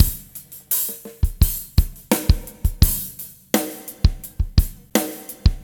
17 rhdrm85.wav